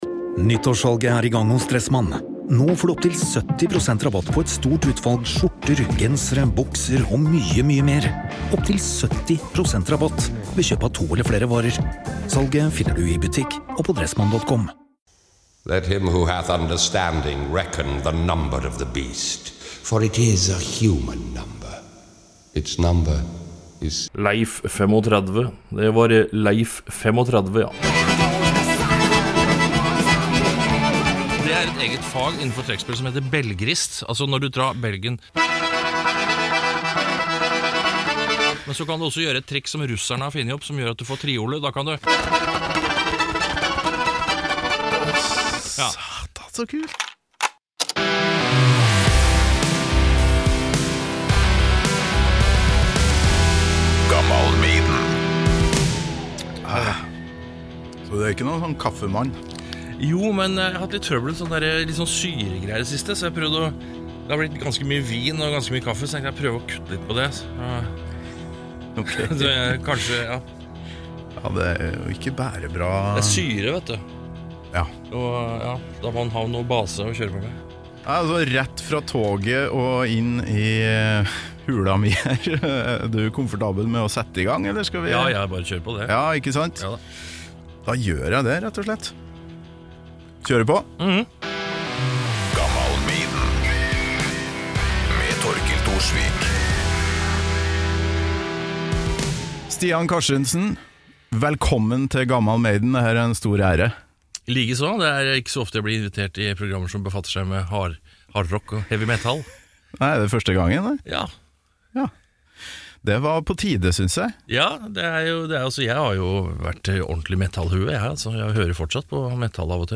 Stian har med seg trekkspellet og vil gjerne sende inn en søknad til jobben som Iron Maidens sjuende bandmedlem.